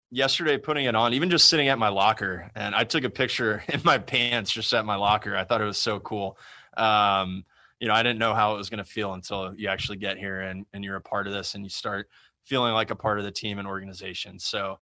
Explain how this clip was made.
“The Yankee pinstripes are special,” he said on a Zoom call Friday. “Every sports fan knows what the Yankee pinstripes mean.”